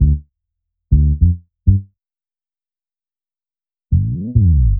FK100BASS2-R.wav